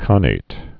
(känāt, kănāt)